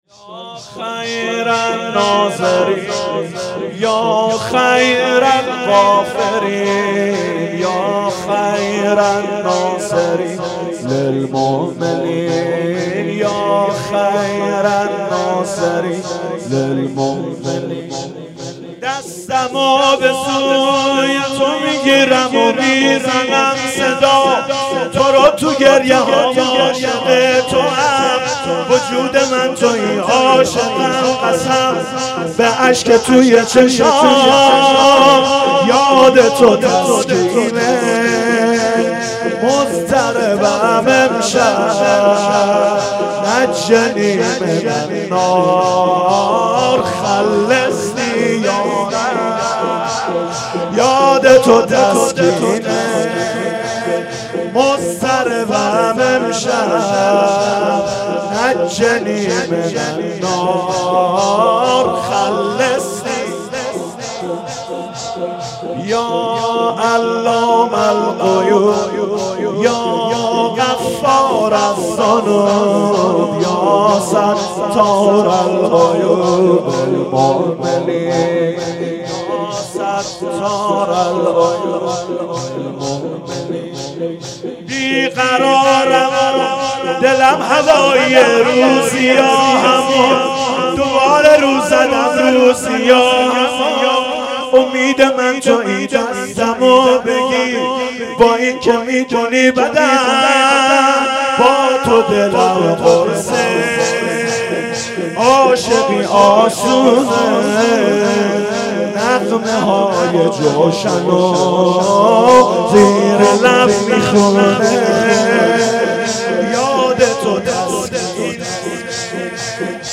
شور
حسینیه بنی فاطمه(س)بیت الشهدا